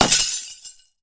ice_spell_impact_shatter_09.wav